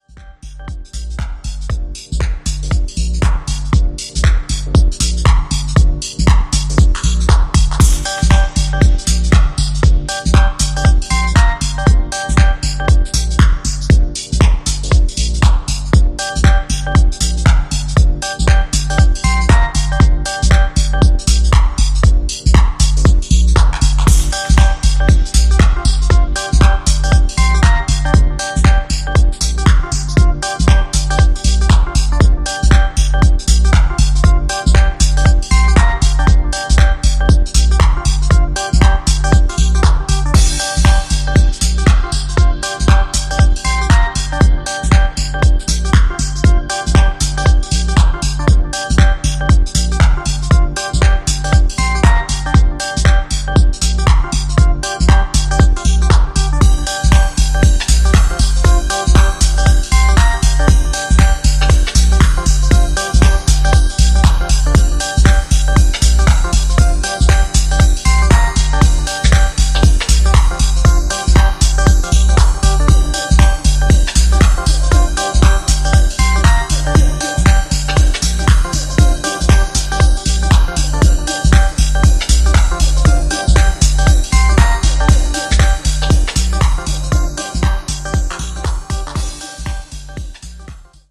今回は、シカゴスタイルのディープハウスに仕上がったグッドな1枚です！
ジャンル(スタイル) DEEP HOUSE / HOUSE